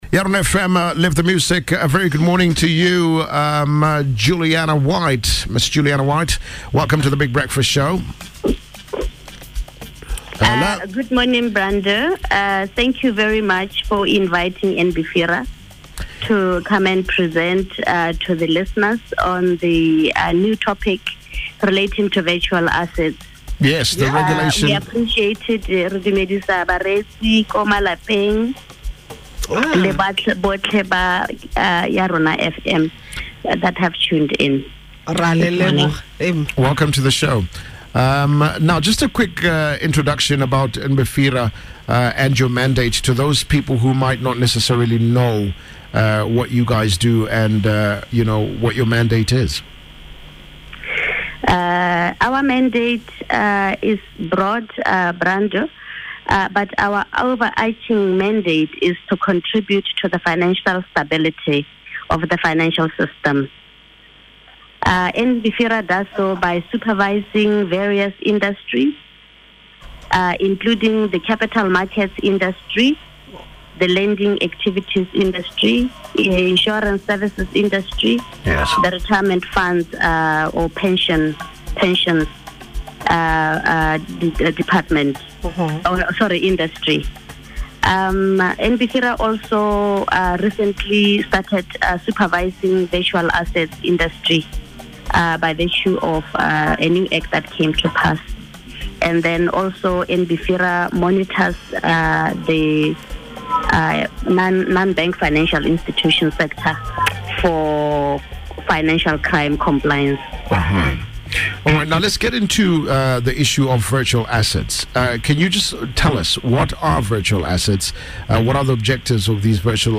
Virtual Assets interview on Yarona FM.mp3